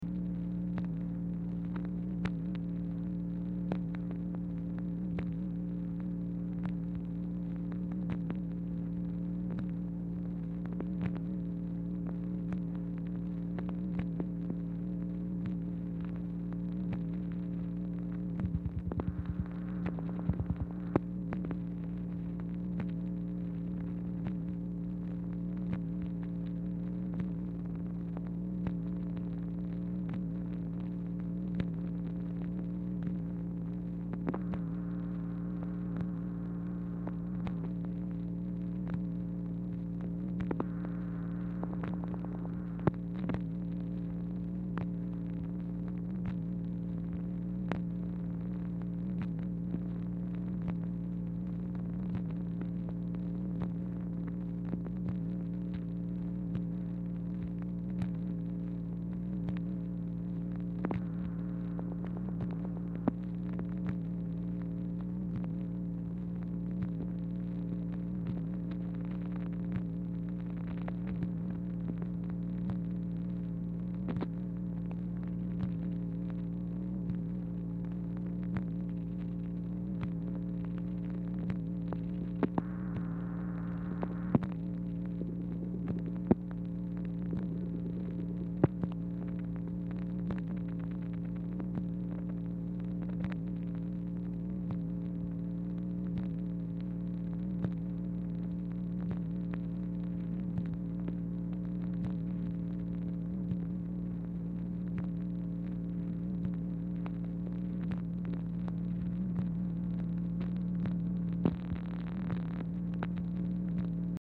Telephone conversation # 422, sound recording, MACHINE NOISE, 12/11/1963, time unknown | Discover LBJ
Format Dictation belt
Series White House Telephone Recordings and Transcripts Speaker 2 MACHINE NOISE Specific Item Type Telephone conversation